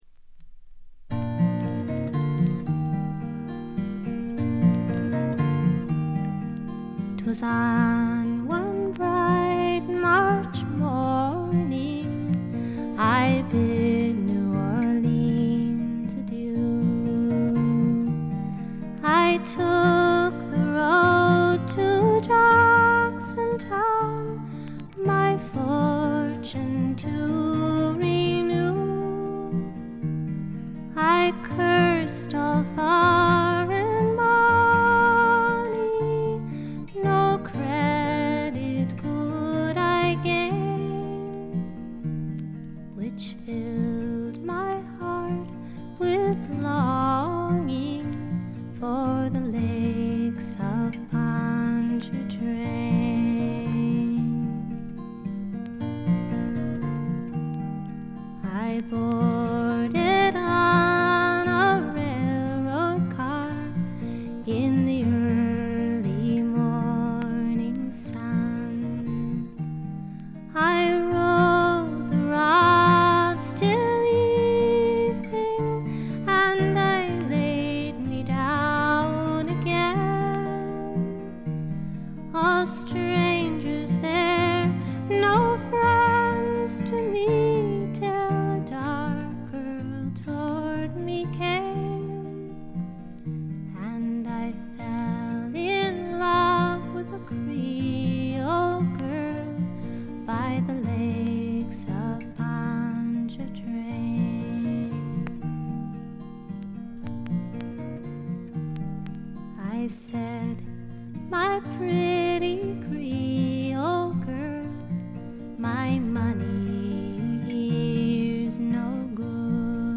内容は自身の弾き語りを中心にした静謐でピュアで美しいフォーク・アルバムです。
試聴曲は現品からの取り込み音源です。
Vocals, Guita, Psalteryr